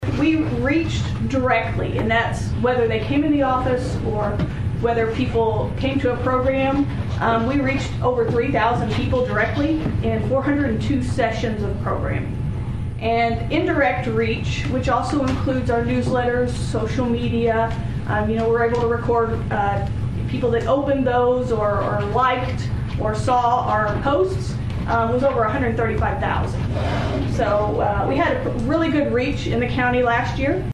St. Francois Co., Mo. (KFMO) - During Tuesday’s St. Francois County Commission meeting, commissioners heard an annual report from the University of Missouri Extension highlighting the program’s impact throughout 2025.